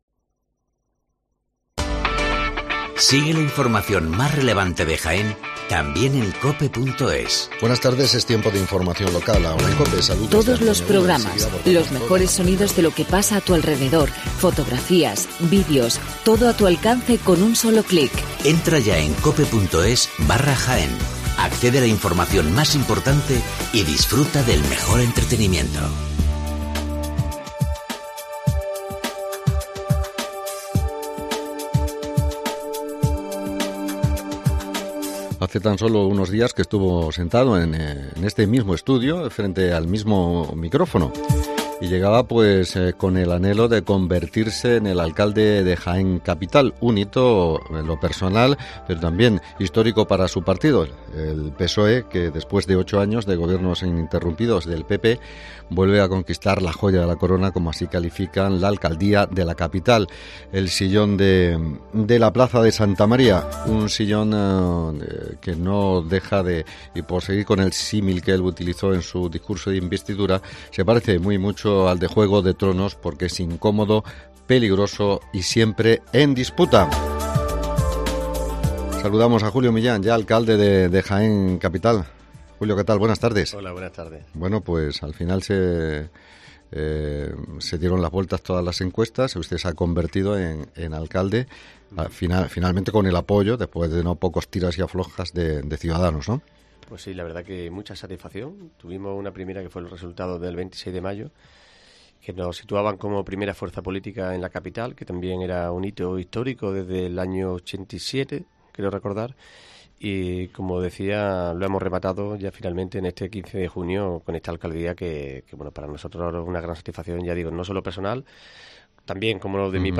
Hoy en Cope hablamos con el alcalde de Jaén, Julio Millán